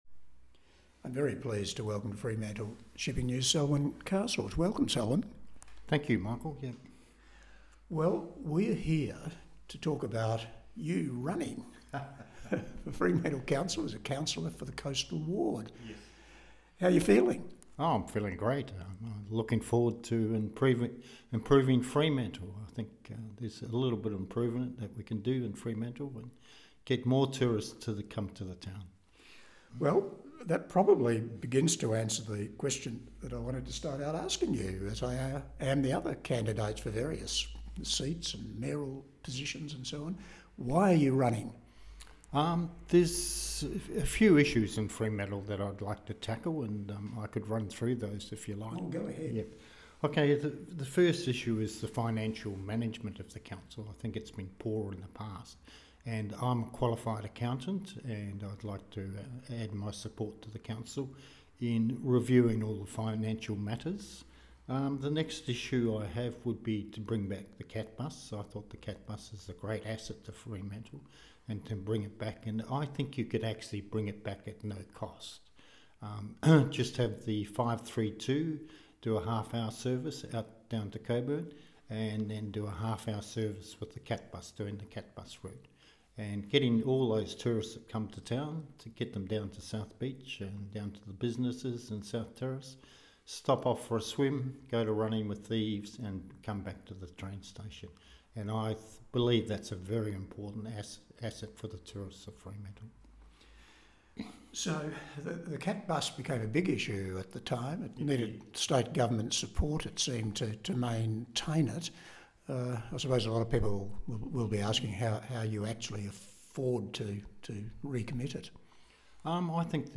The Coastal Ward Candidates Interviews
We have invited all candidates to be interviewed by our Editor in a getting-to -know-you podcast format explaining why they are running.